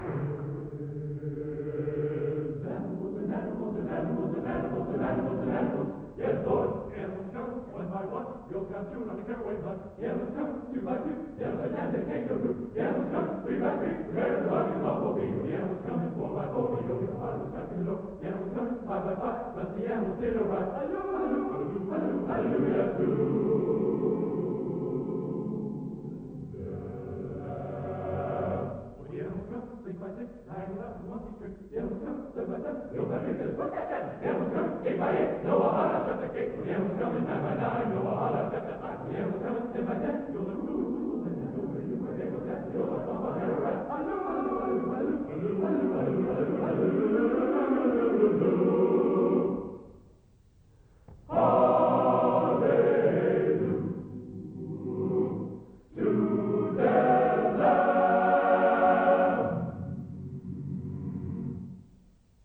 Possible the fastest tempo ever attempted on the second half of this song.